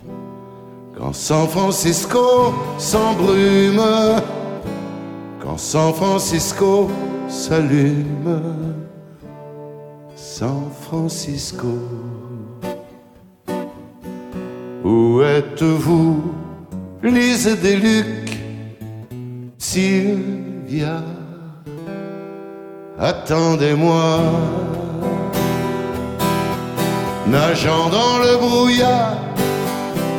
"templateExpression" => "Chanson francophone"